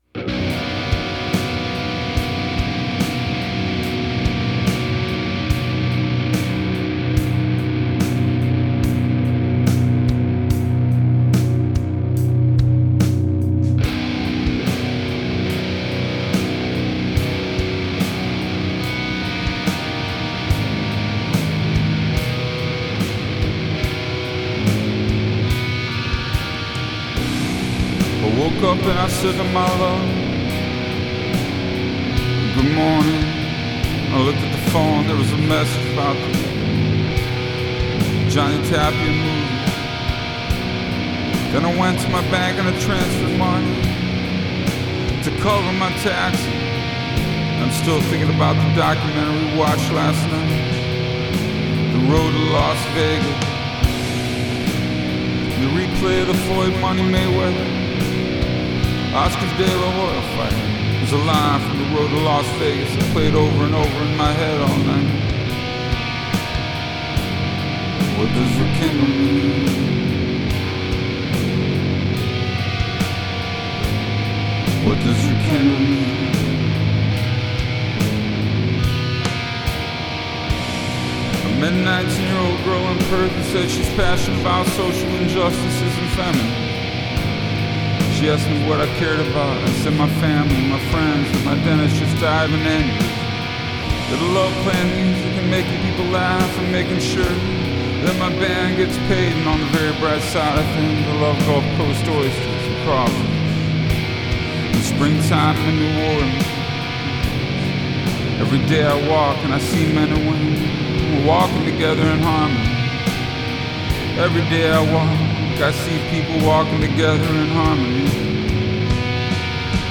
Жанр: Alternative Rock / Post-Metal / Experimental.